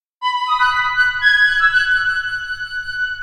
Birdy.ogg